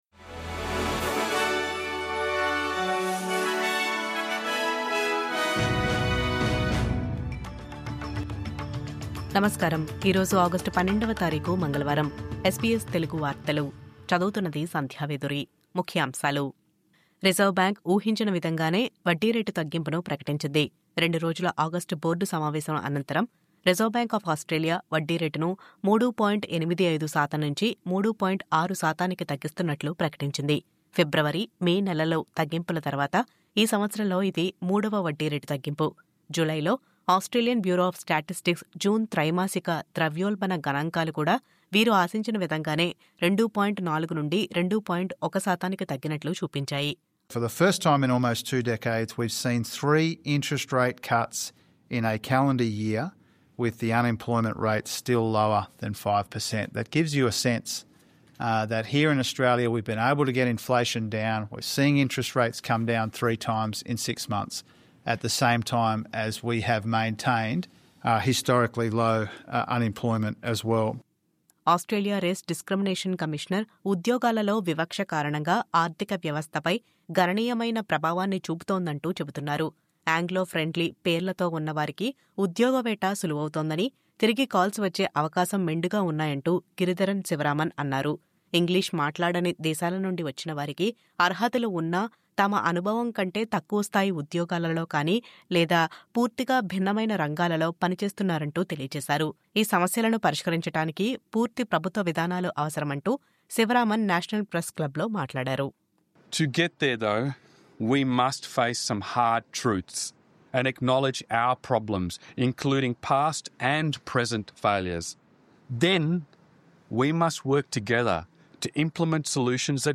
News Update:- ఉద్యోగాలలో పేర్ల వివక్ష ... అర్హత ఉన్నా తక్కువ స్థాయి ఉద్యోగాలు చేస్తున్న వలసదారులు..